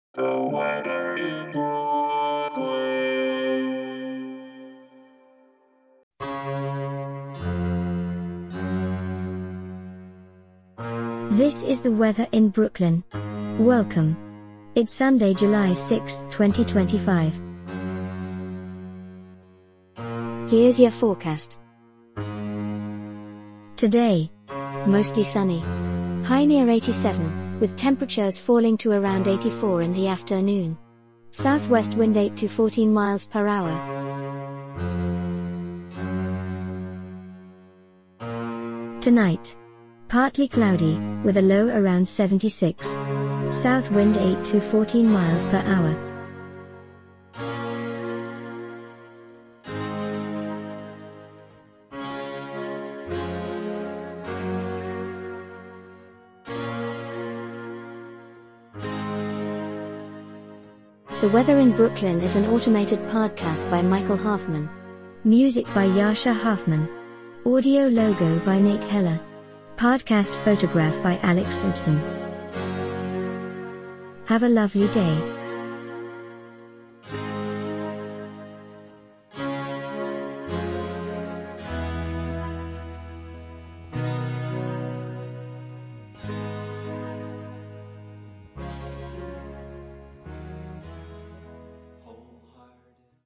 is generated automatically.